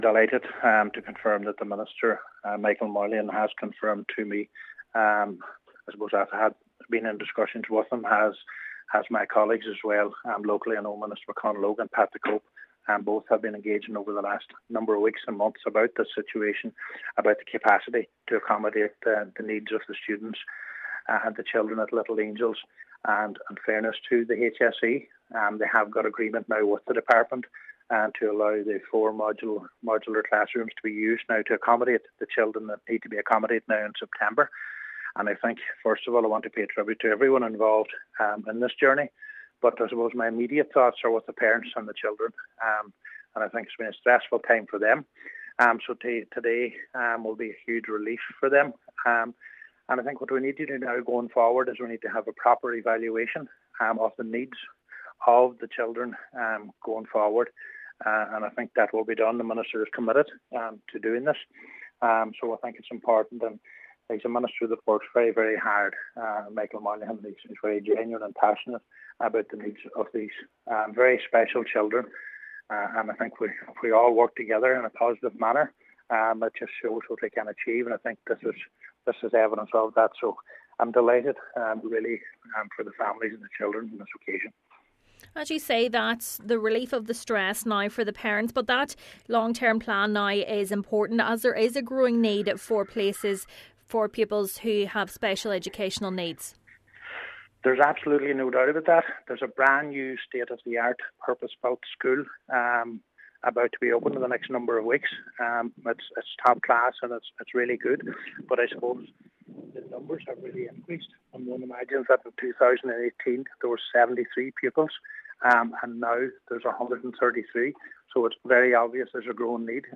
Councillor Ciaran Brogan while welcoming the news, says the focus must now shift to develop a long-term plan to ensure all children with additional educational needs have a school place: